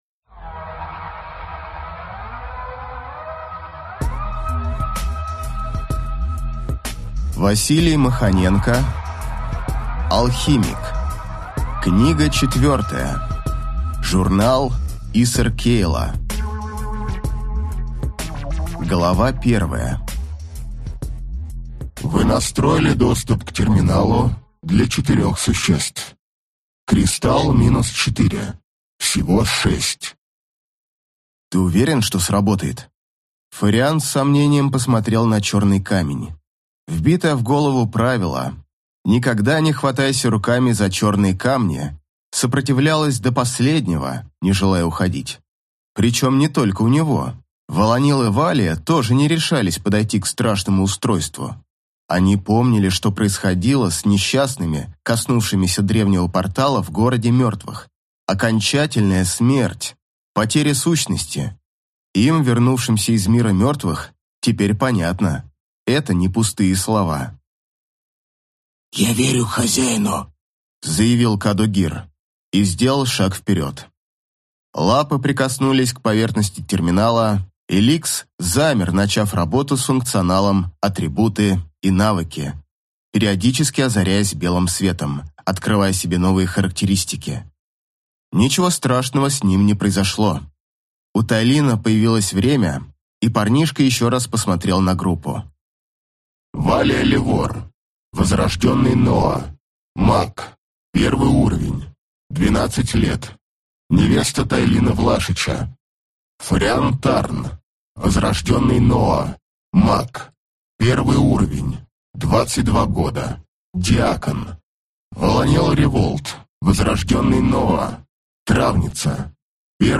Аудиокнига Алхимик. Журнал Иср Кейла | Библиотека аудиокниг